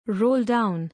roll-down.mp3